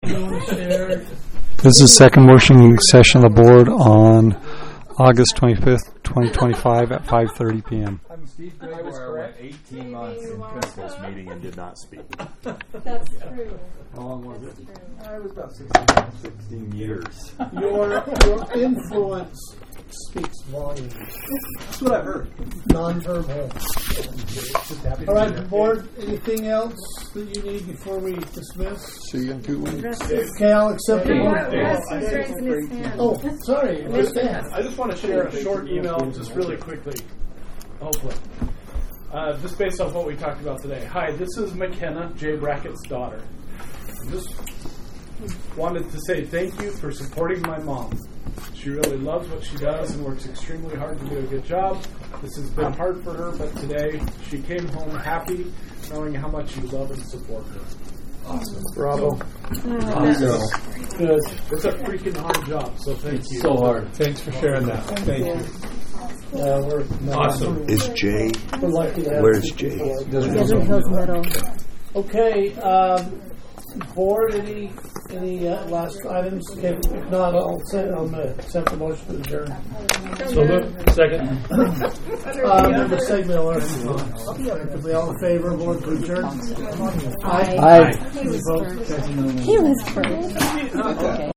Meeting